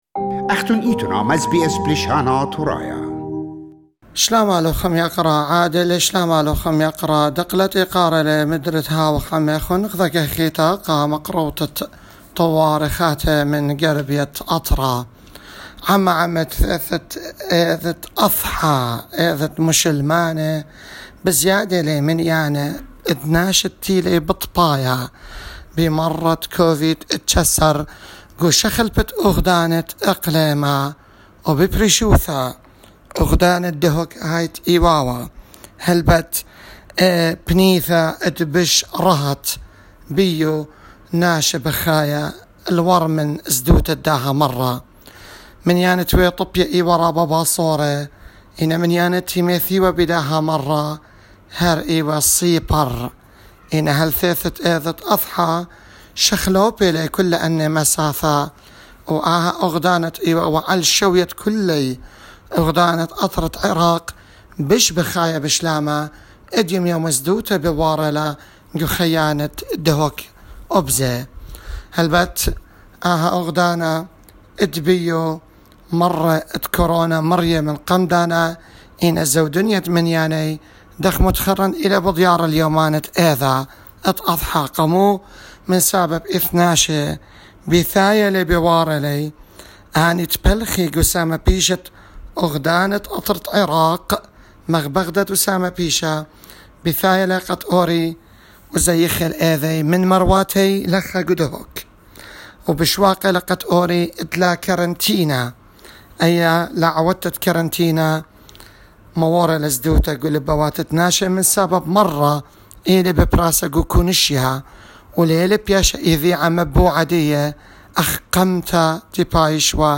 In particular, he outlined the surge of cases in cities like Duhok in which once had one of the lowest numbers in the country. SBS reporter also mentioned that during Eid al-Adha, many people from other parts of Iraq visited the north.